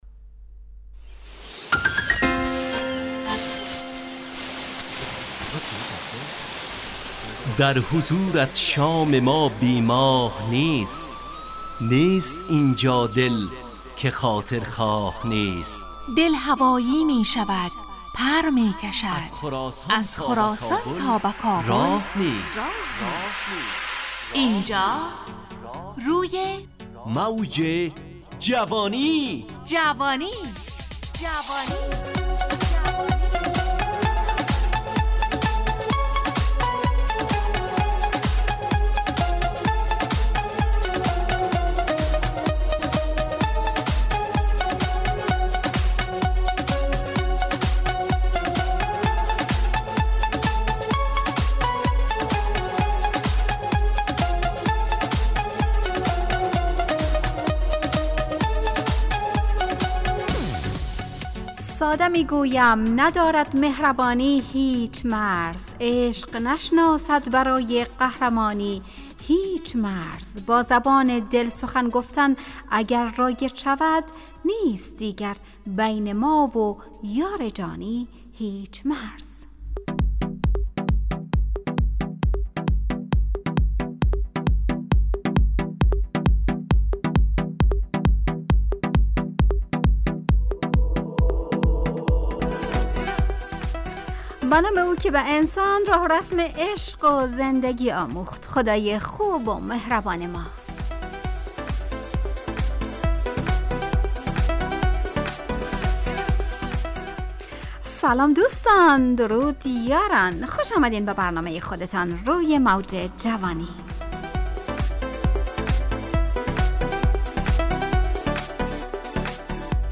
همراه با ترانه و موسیقی مدت برنامه 55 دقیقه . بحث محوری این هفته (دانشجو) تهیه کننده